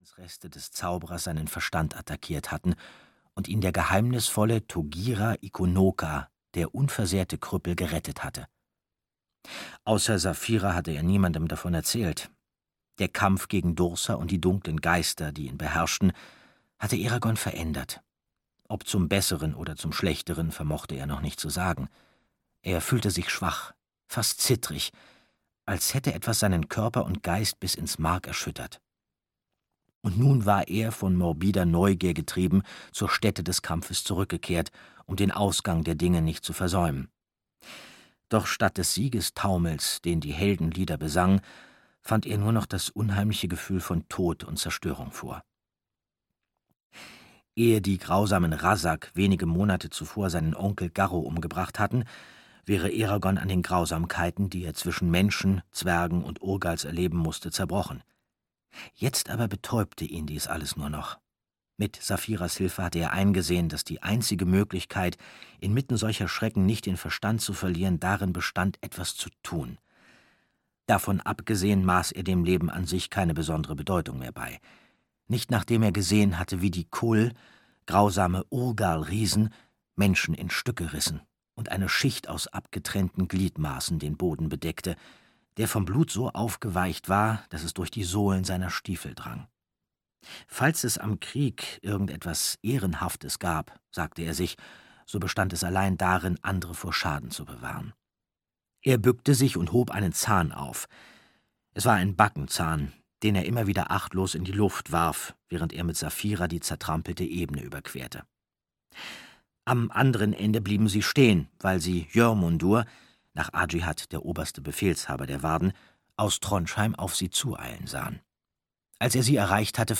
Eragon - Der Auftrag des Ältesten (DE) audiokniha
Ukázka z knihy
• InterpretAndreas Fröhlich